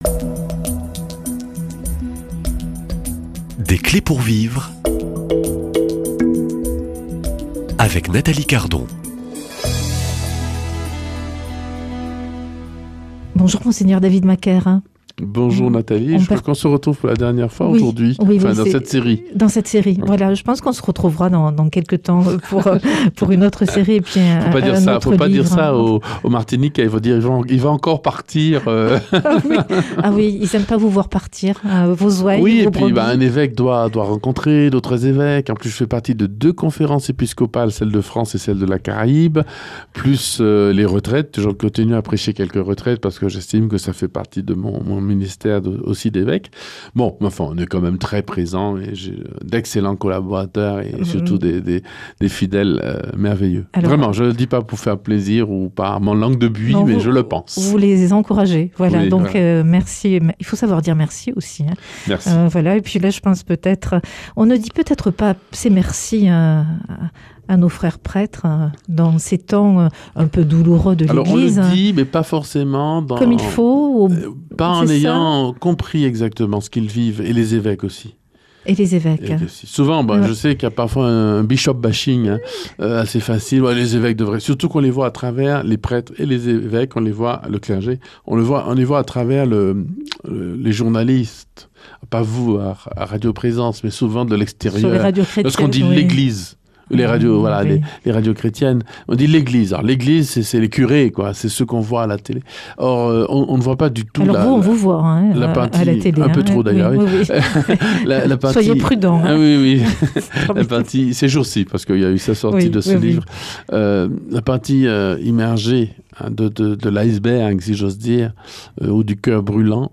Invité : Mgr David Macaire, ordonné prêtre chez les Dominicains à Toulouse en 2001.